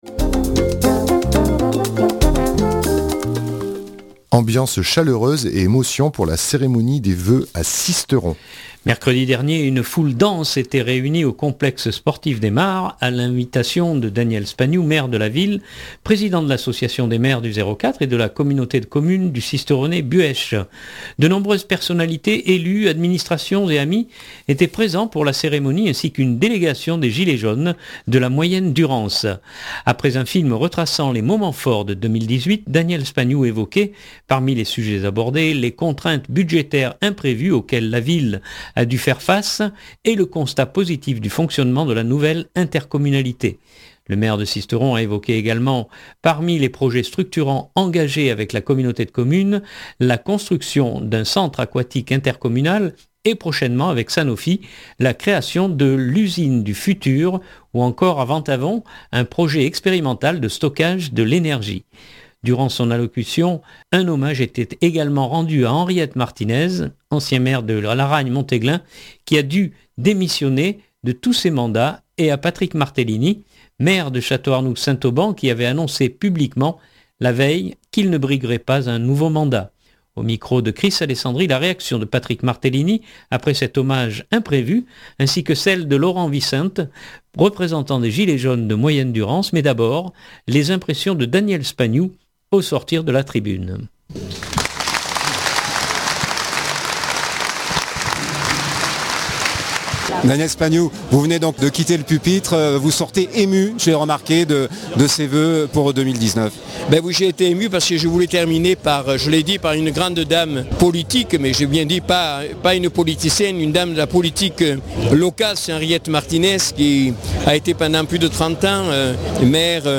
Ambiance chaleureuse et émotions pour la cérémonie des vœux à Sisteron !
Mais d’abord les impressions de Daniel Spagnou au sortir de la tribune.